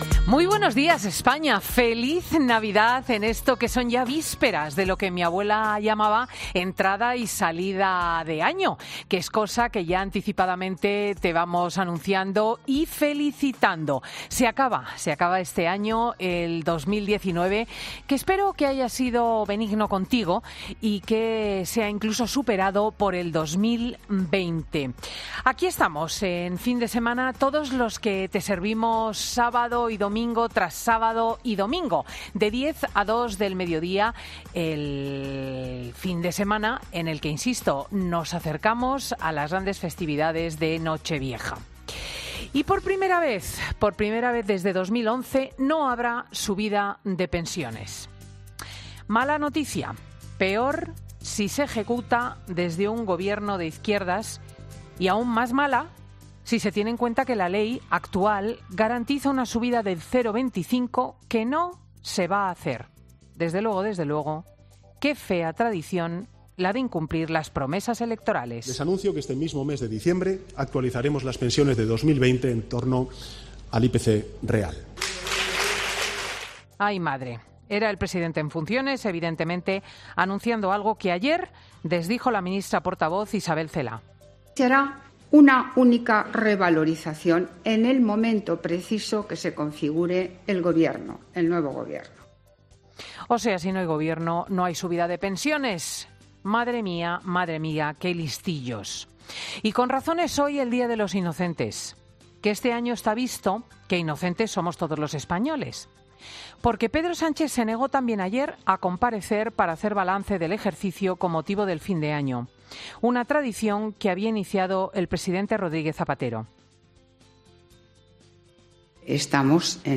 Monólogo de Cristina López Schlichting del 28 de diciembre de 2019